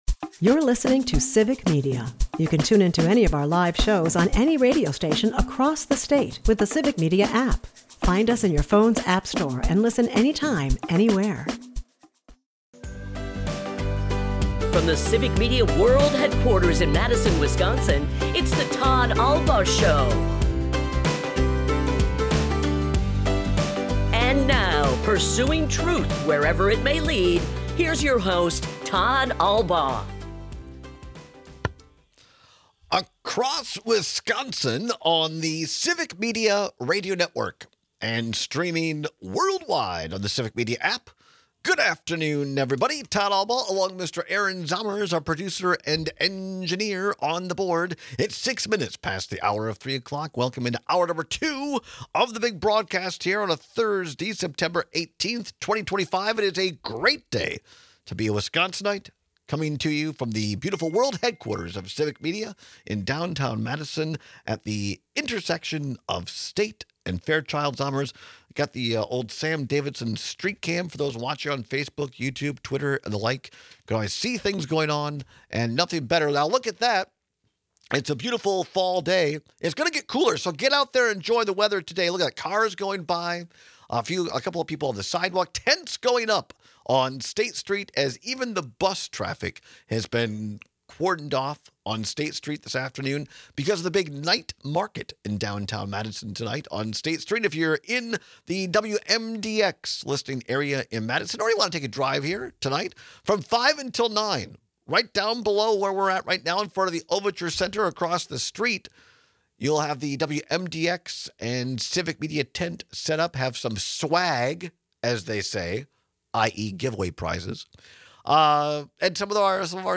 We take a bunch of calls and texts on which protein you prefer.